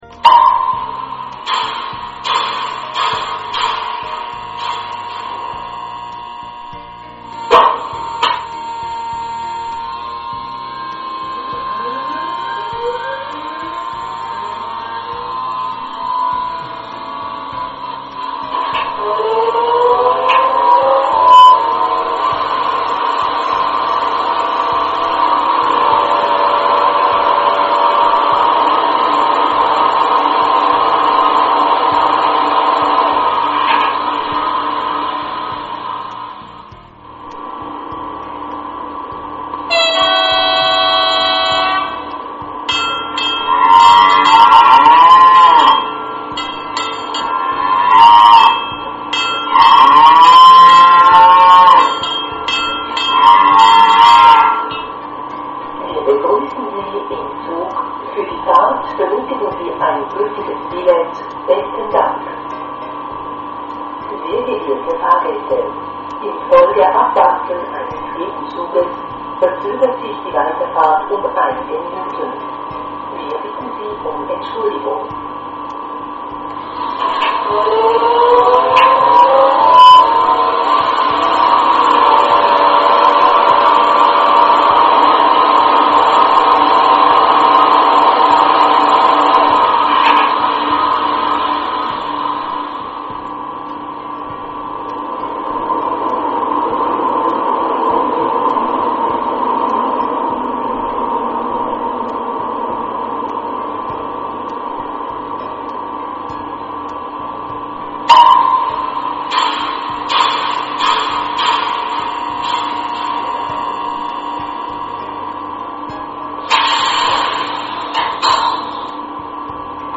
Hierzu werden Soundaufnahmen direkt am Vorbild vorgenommen und dann im Soundlabor für die Elektronik abgeglichen.
• Einschaltphase
• Standgeräusch
• Fahrgeräusch
Die Hintergrundmusik in den MP3-Demo Dateien ist nicht im XLS-Modul vorhanden!
Soundgeräusch